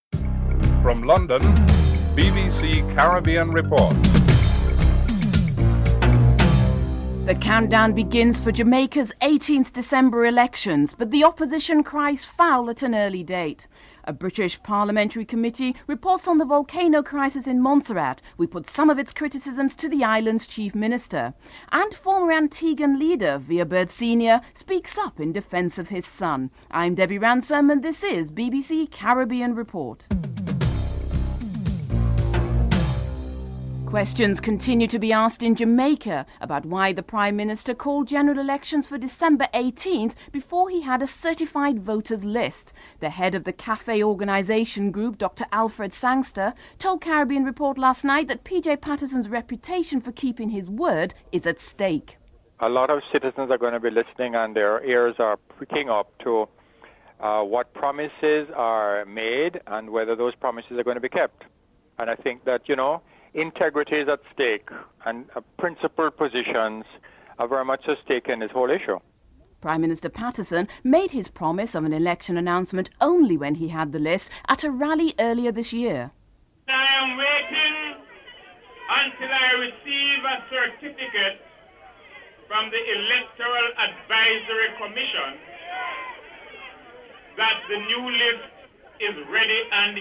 1. Headlines (00:00-00:25)
Cricket Commentator Colin Croft reports (12:11-15:31)